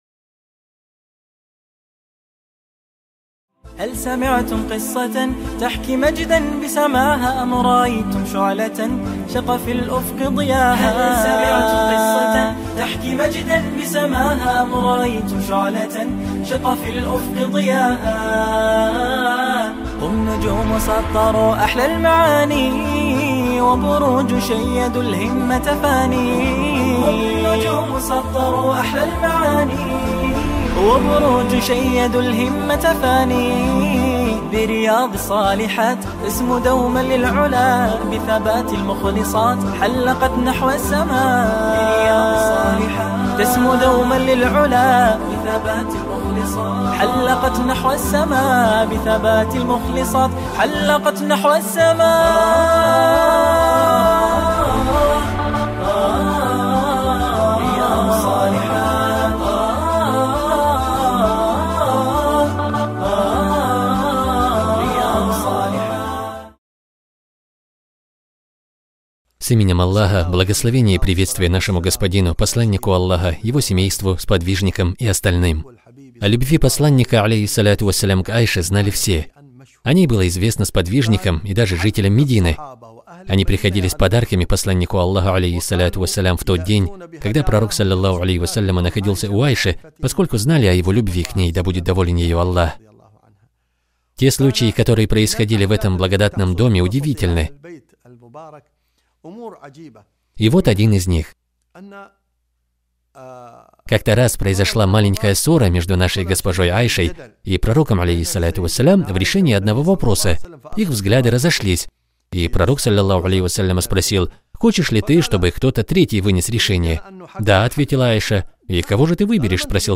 Цикл лекций «Совершенные женщины»